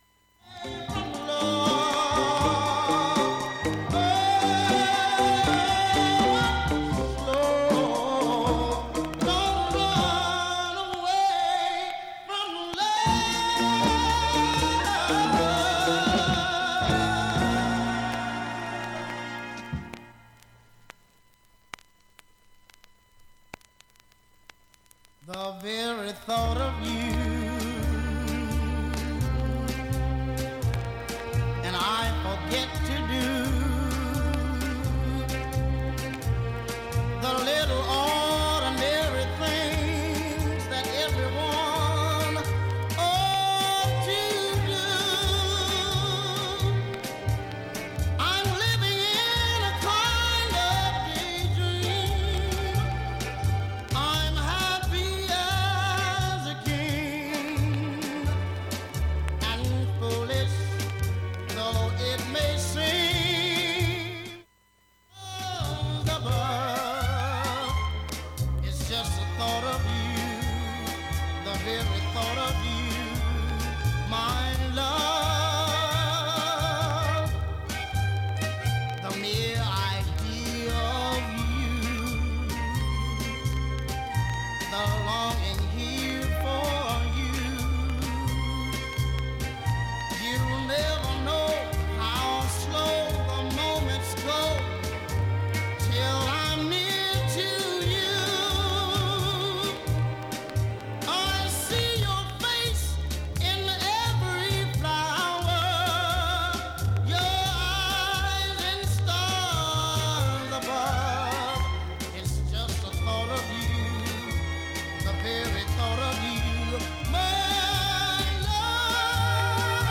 ずっと周回プツが出ています。
たまにわずかなプツが出ます。
現物の試聴（上記録音時間6分）できます。音質目安にどうぞ